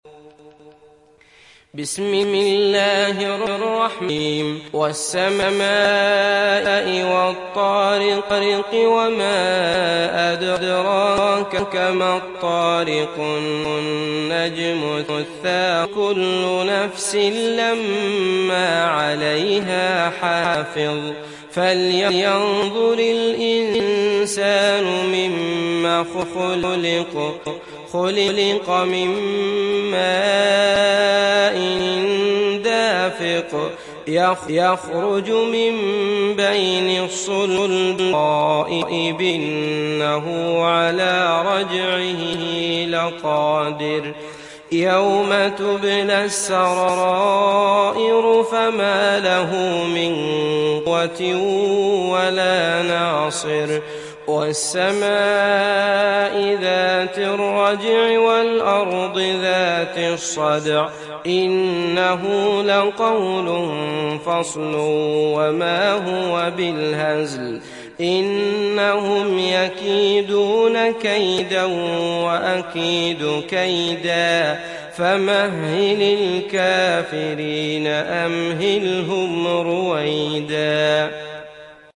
Sourate At Tariq Télécharger mp3 Abdullah Al Matrood Riwayat Hafs an Assim, Téléchargez le Coran et écoutez les liens directs complets mp3